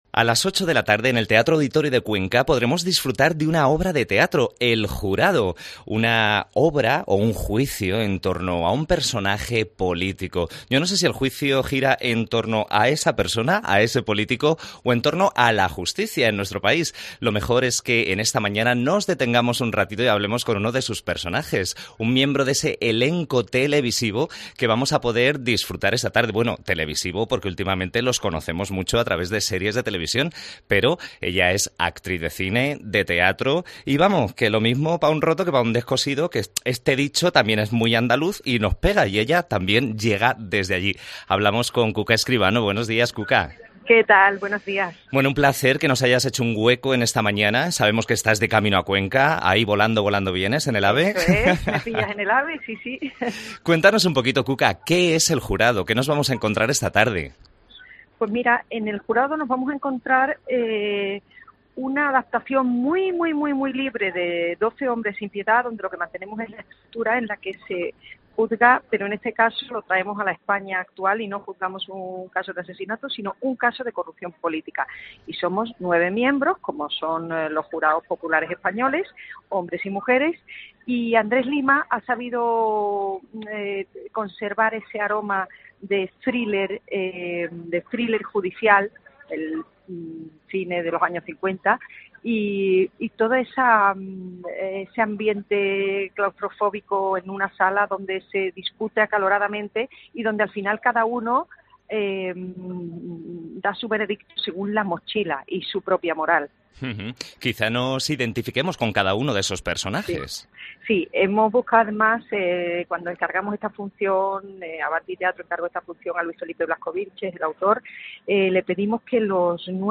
AUDIO: Entrevista a Cuca Escribano, una de las intérpretes de "El jurado" que se representará en 25 de mayo a las 20:00 h. en el Teatro Auditorio de...